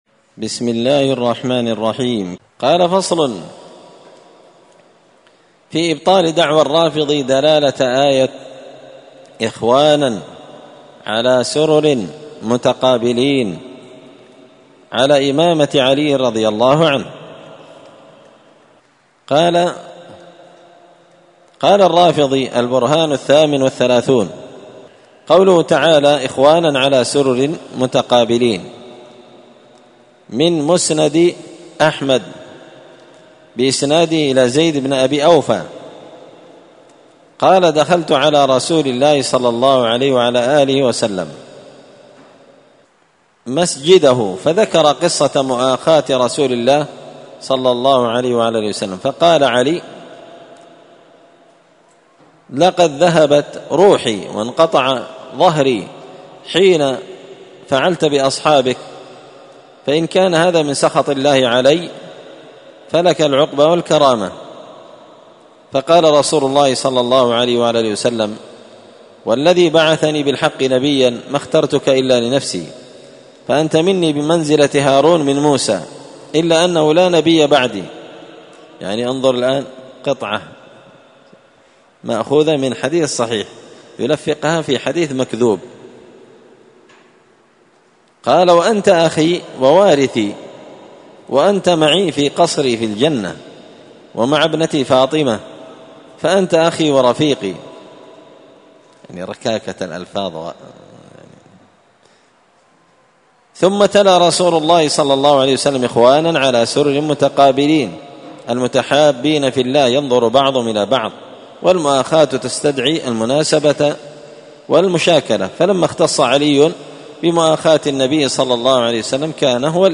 الأربعاء 14 صفر 1445 هــــ | الدروس، دروس الردود، مختصر منهاج السنة النبوية لشيخ الإسلام ابن تيمية | شارك بتعليقك | 86 المشاهدات
مسجد الفرقان قشن_المهرة_اليمن